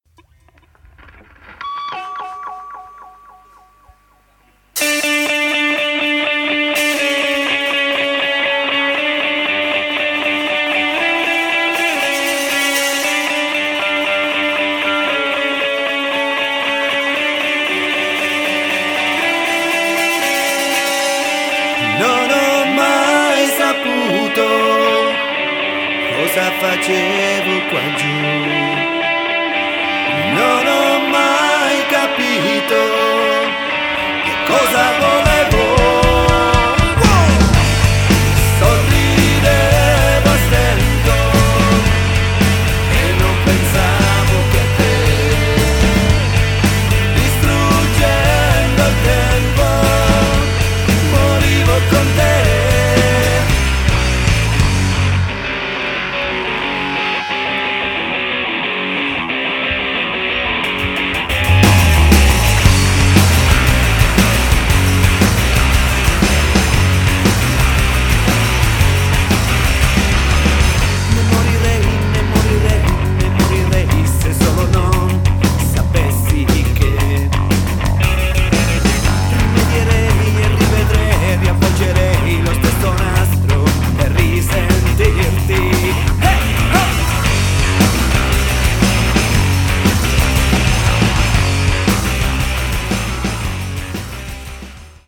rock music
Genere: Rock.